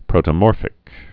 (prōtə-môrfĭk)